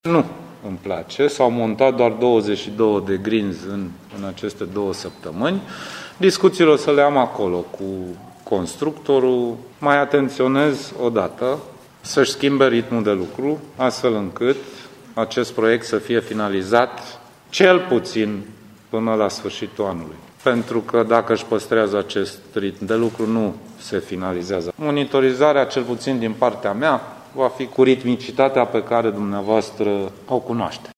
Sorin Grindeanu cere insistent constructorului să se mobilizeze, astfel încât lucrarea să fie gata până la finalul luii 2022: